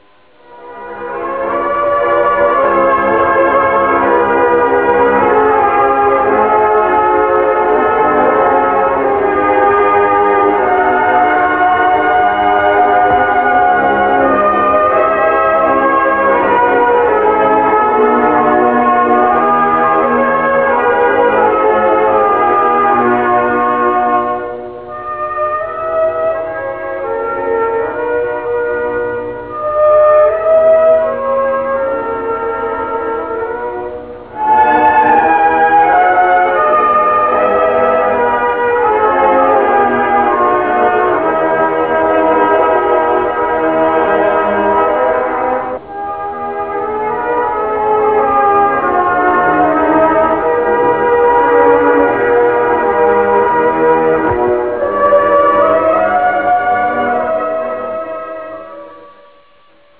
da opere sacre
Original Track Music